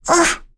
Jane-Vox_Damage_03.wav